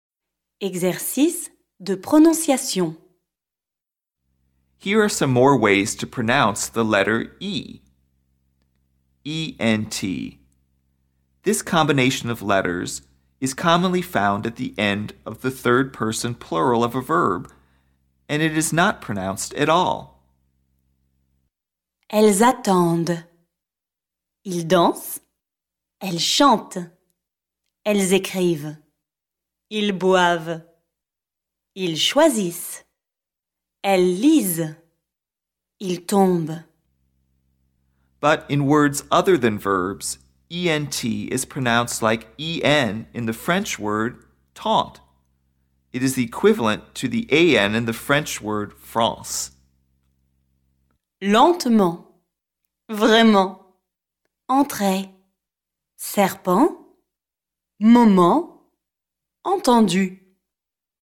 PRONONCIATION
ent – This combination of letters is a common ending of a verb form (3rd person plural — covered later!), and it is not pronounced at all.
However, in words other than verbs, “ent” is pronounced like the sound “en” in the French word “tente.” It is also equivalent to the sound “an” in the French word “France.”